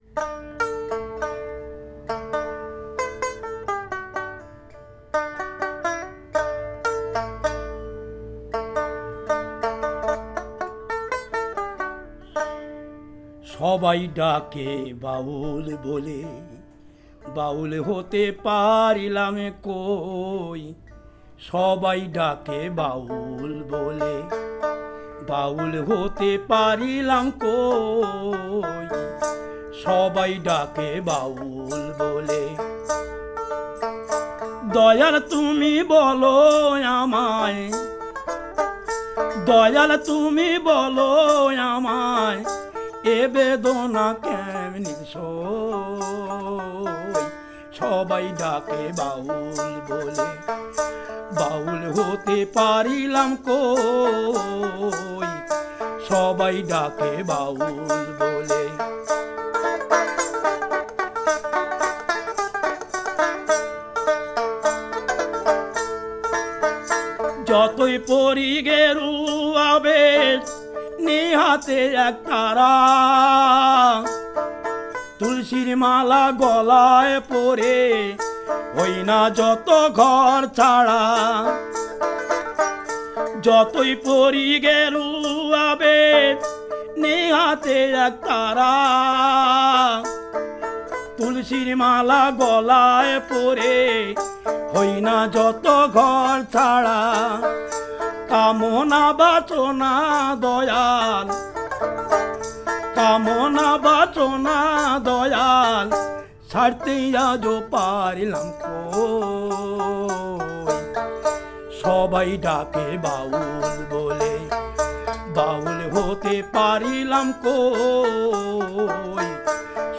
cantor de baul
Baul singer